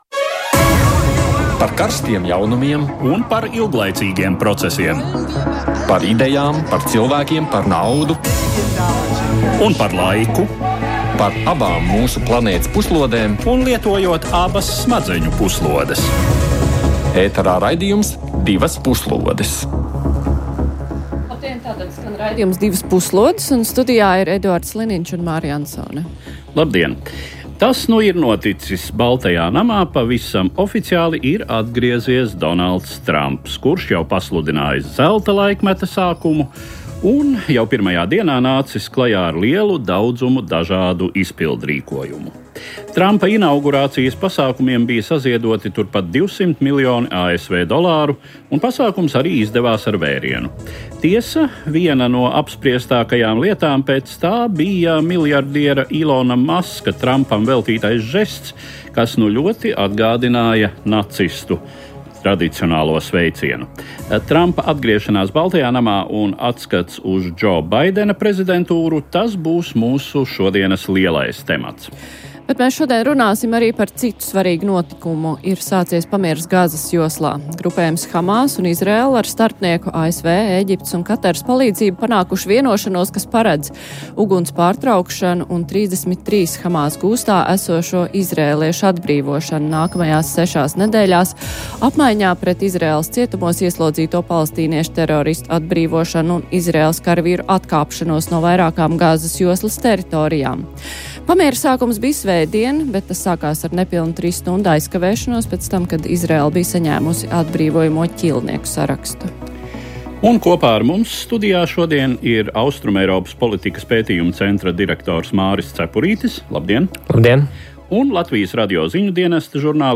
Raidījums par ārpolitikas aktualitātēm, kurā kopā ar ekspertiem un ārpolitikas pārzinātājiem apspriežam un analizējam nedēļas svarīgākos notikumus pasaulē.